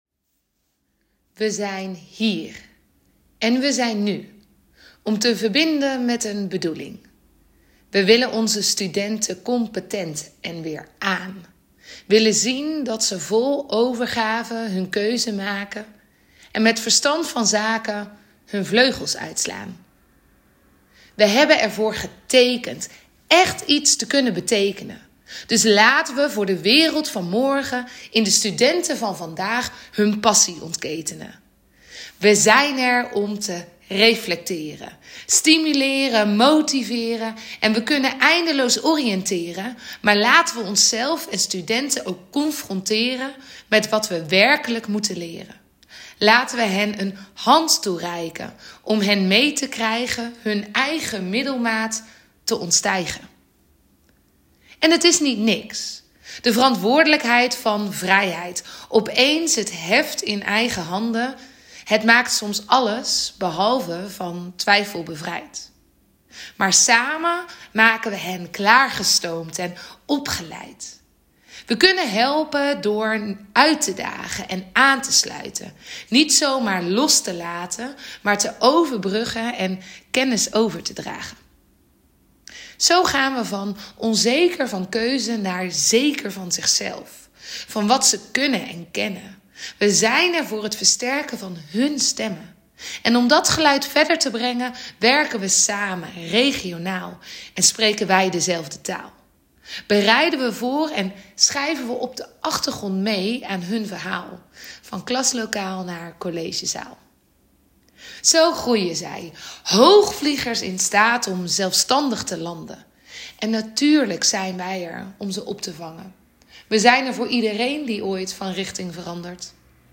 Zo ook voor de werkconferentie Opnieuw verbinden.